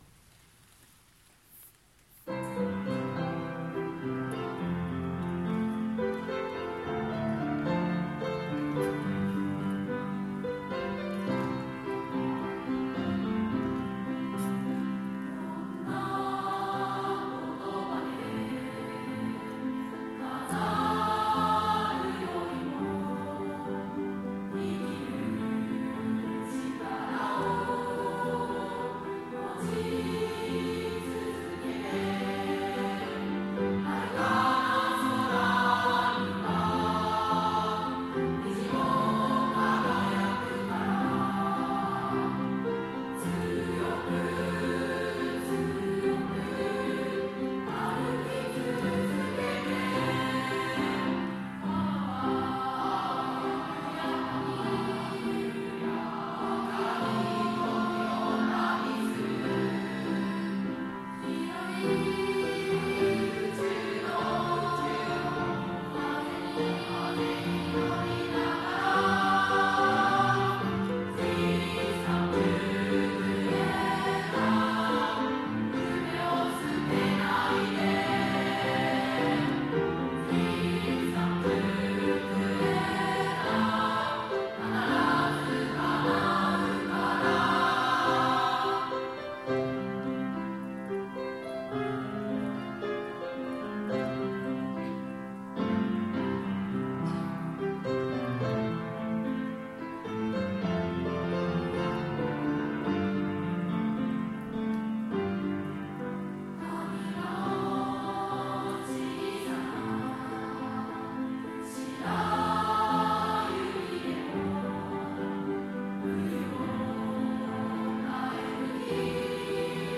令和2年度合唱発表会の歌声＜1年2組＞
令和2年10月24日（土）に開催しました文化発表会の合唱を掲載します。